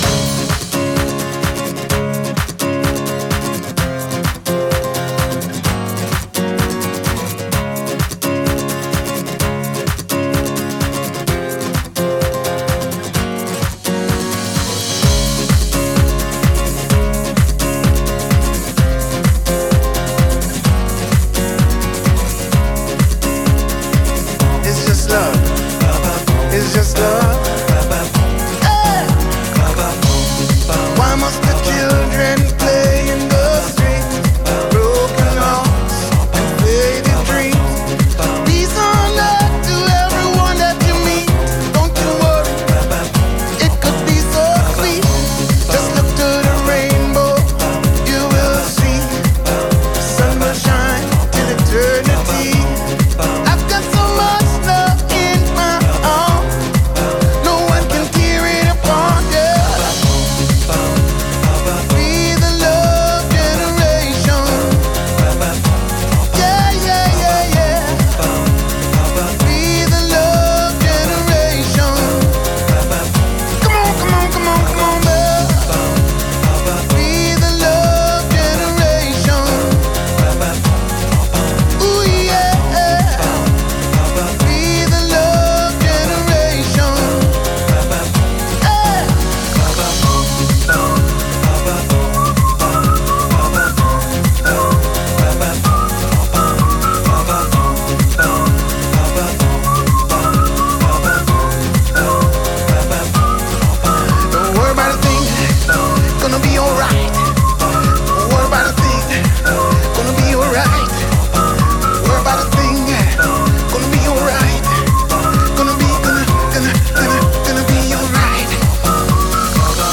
Главная » Файлы » Стиль музыки » Club & Dance